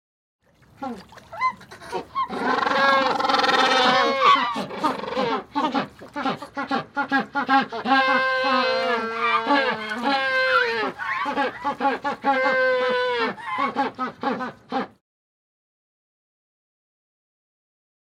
Small group of Magellanic penguins, screams 3
• Category: Penguin